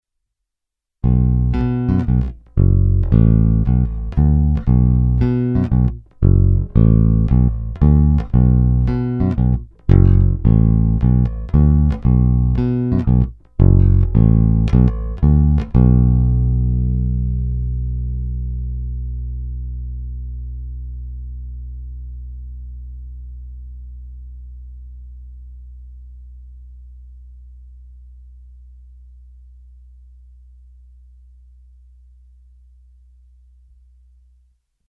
Není-li uvedeno jinak, následující nahrávky jsou vyvedeny rovnou do zvukové karty a vždy s plně otevřenou tónovou clonou a s korekcemi v nulové poloze, následně jsou jen normalizovány, jinak ponechány bez úprav.
Snímač u krku